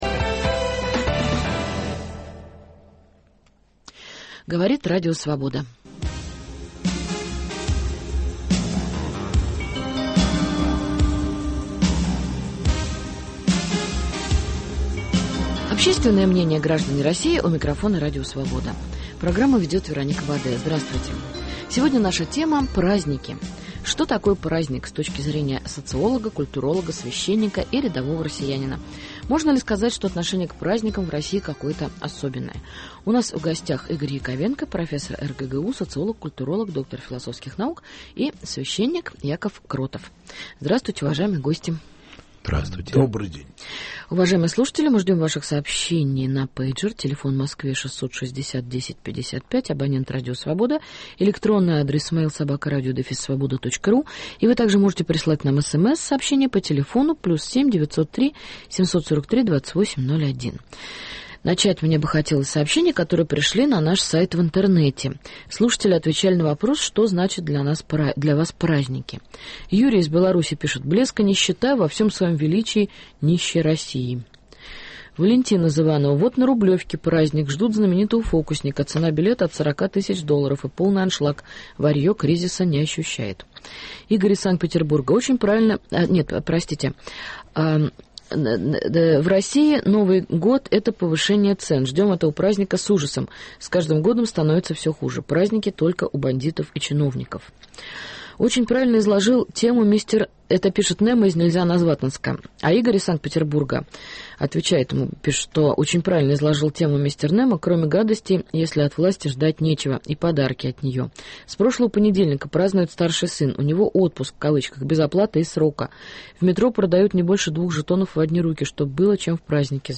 Что значат для вас праздники? Праздник с точки зрения социолога и священника. Гости передачи – социолог, культуролог, доктор философии